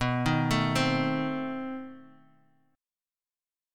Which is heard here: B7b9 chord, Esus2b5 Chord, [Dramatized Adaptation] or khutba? B7b9 chord